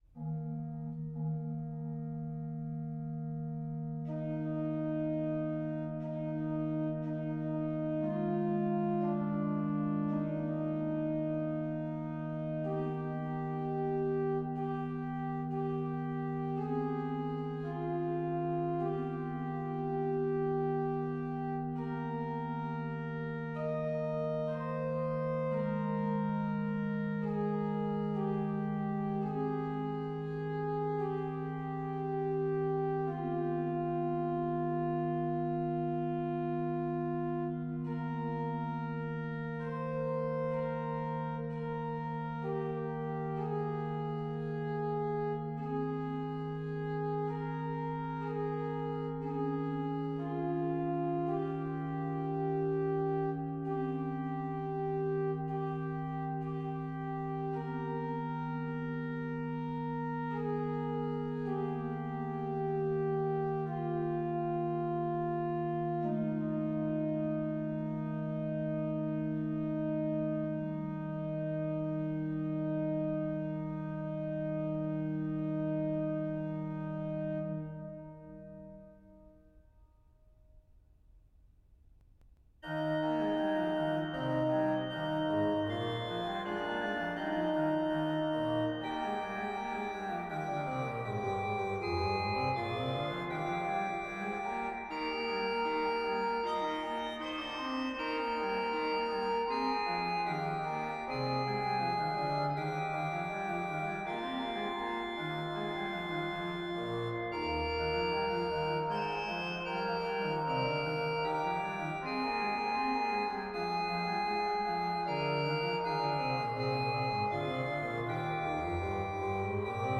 organ Click to listen.